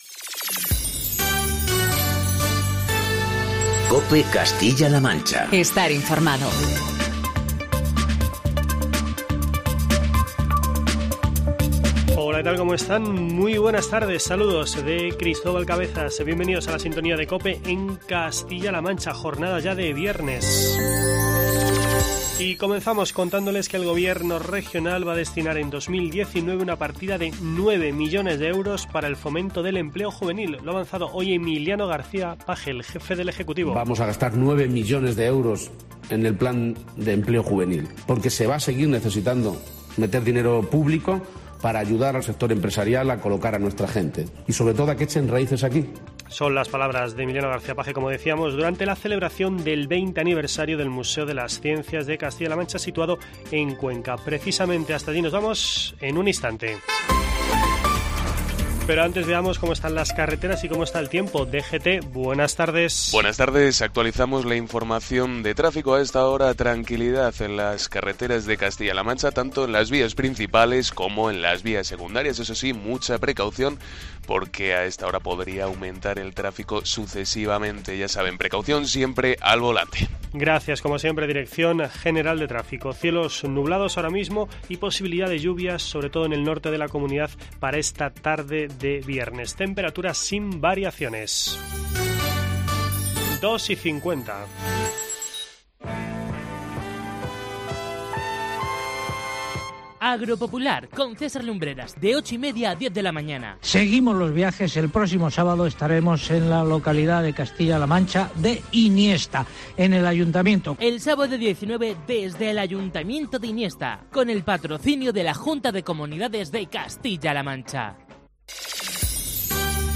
Toda la actualidad de Castilla-La Mancha en "Mediodía COPE".